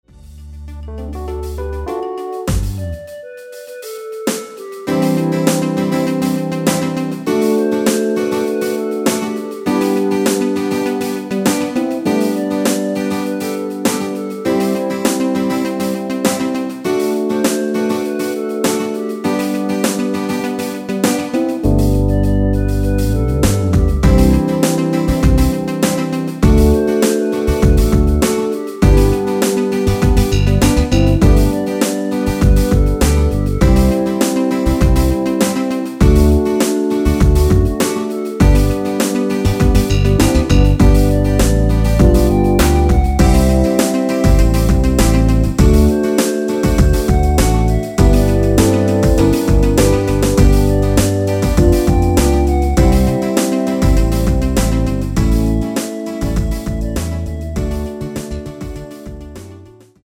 원키 멜로도 포함된 MR입니다.
앞부분30초, 뒷부분30초씩 편집해서 올려 드리고 있습니다.
중간에 음이 끈어지고 다시 나오는 이유는